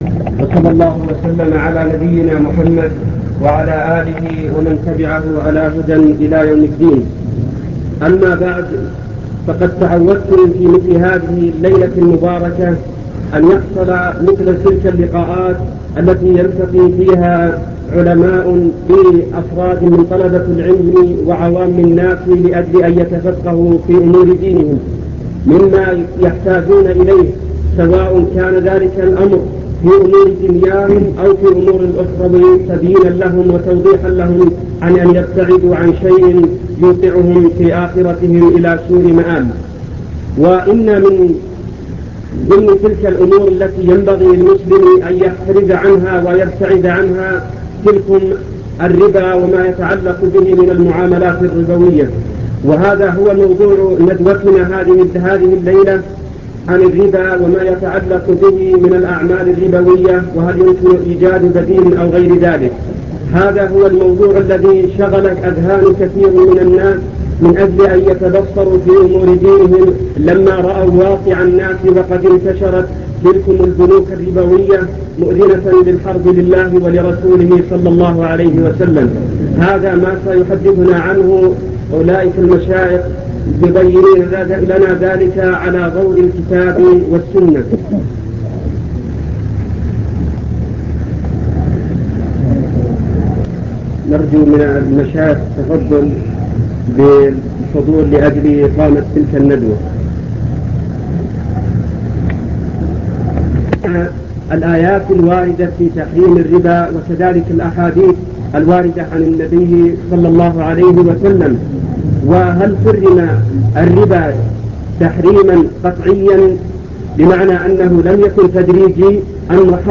المكتبة الصوتية  تسجيلات - محاضرات ودروس  الربا وما يتعلق به من أحكام